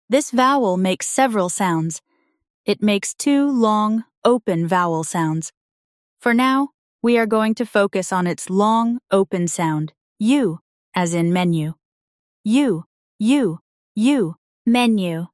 For now, we are going to focus on its long, open sound, /ū/, as in “menu”.